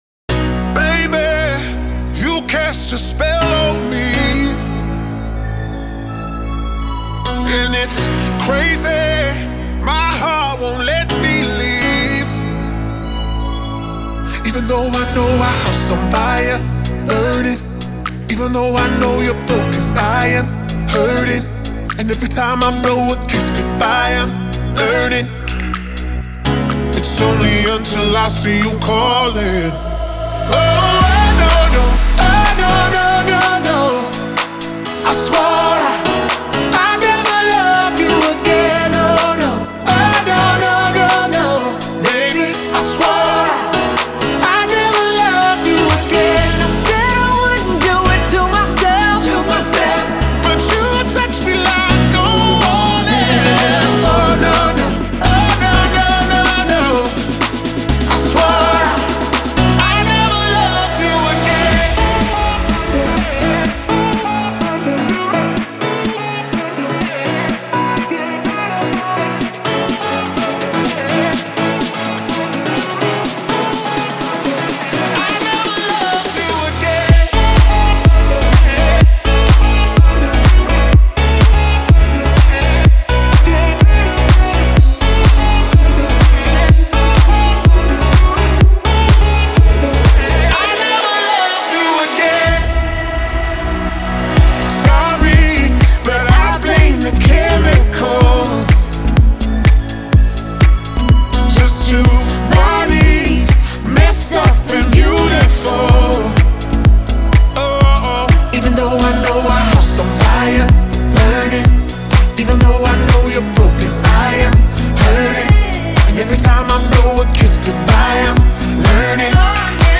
энергичная поп-кантри композиция